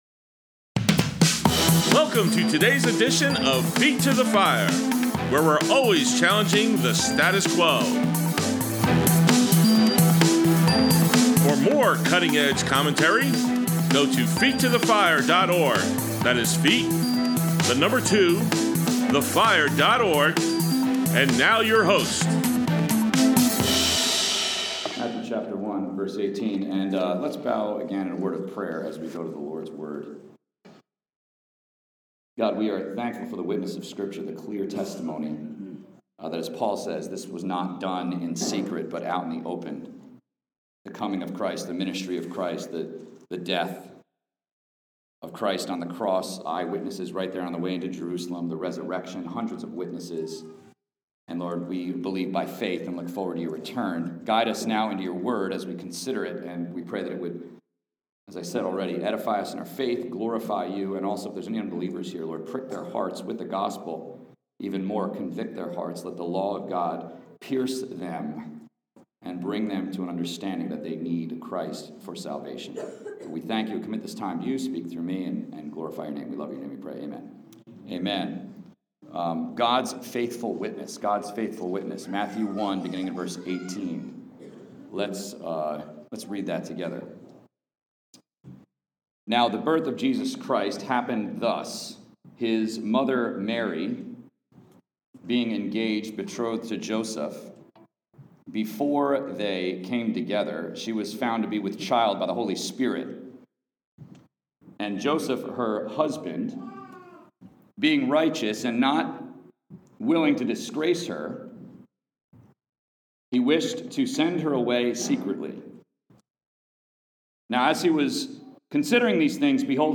Grace Bible Church Christmas Eve Service: December 24, 2023